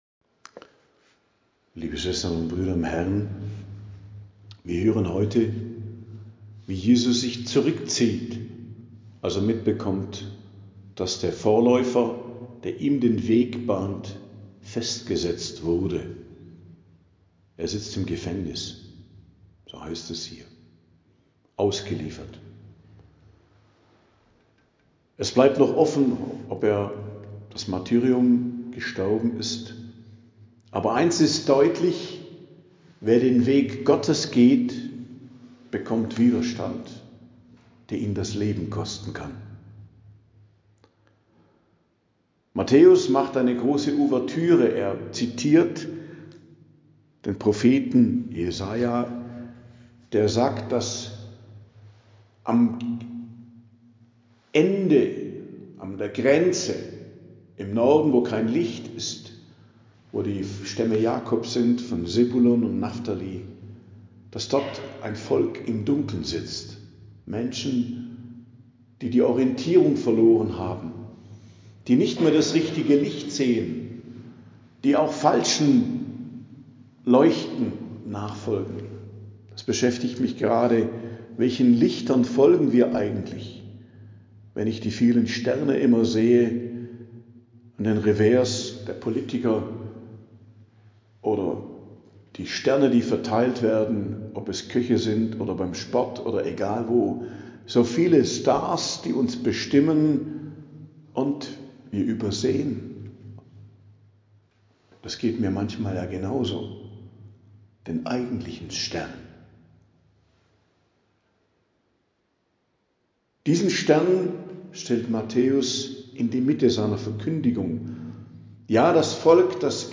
Predigt am 7. Januar, 7.01.2025 ~ Geistliches Zentrum Kloster Heiligkreuztal Podcast